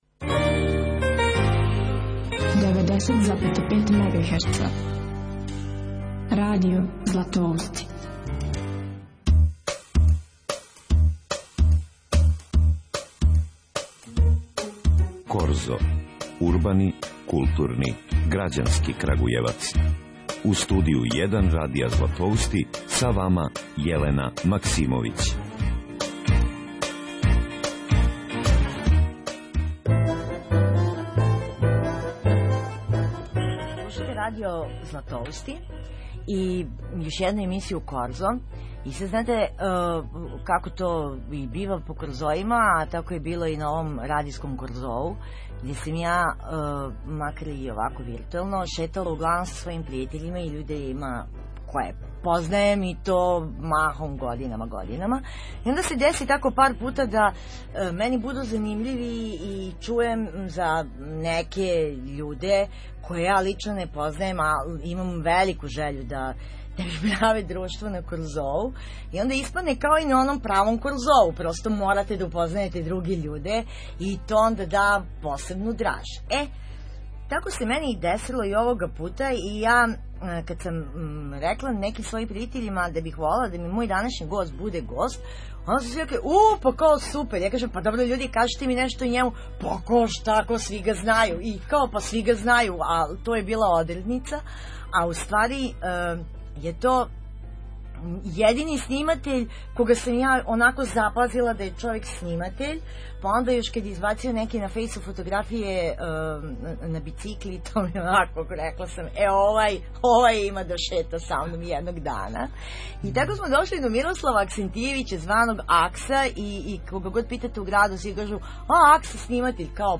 Разговор са истакнутим Крагујевчанима